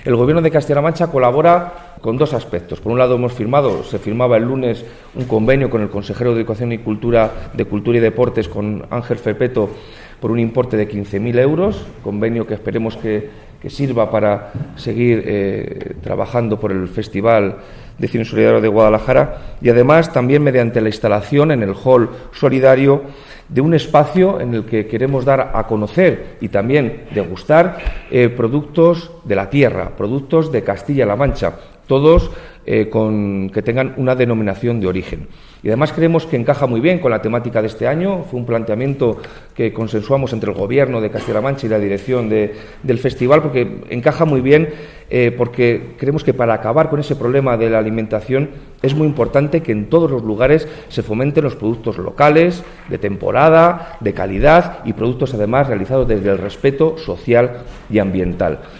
El delegado de la Junta en Guadalajara, Alberto Rojo, habla del apoyo del Gobierno regional al Festival de Cine Solidario de Guadalajara (FESCIGU)